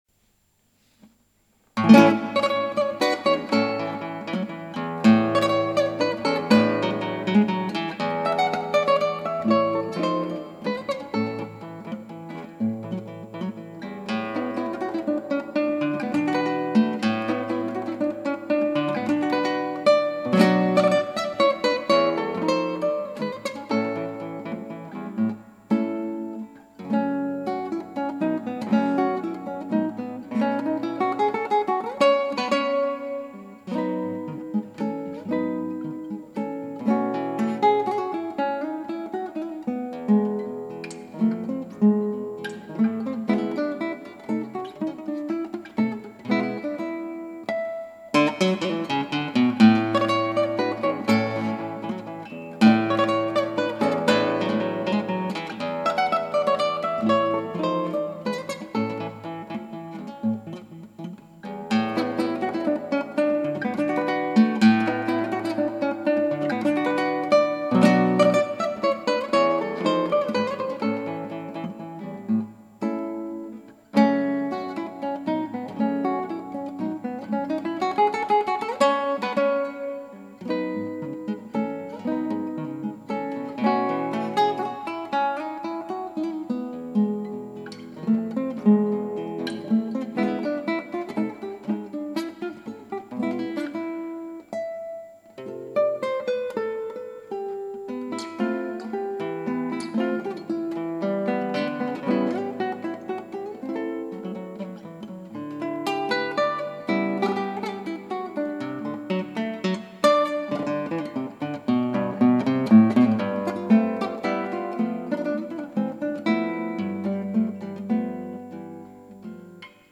クラシックギター　ストリーミング　コンサート
リベンジならずか・・・。うまくいかねぇなぁ